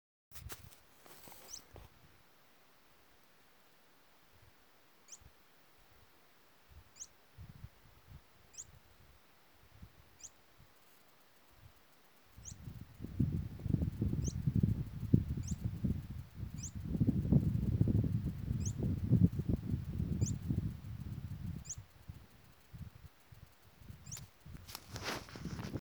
Canastero Coludo (Asthenes pyrrholeuca)
Nombre en inglés: Sharp-billed Canastero
Fase de la vida: Adulto
Provincia / Departamento: La Pampa
Localidad o área protegida: Casa de Piedra
Condición: Silvestre
Certeza: Observada, Vocalización Grabada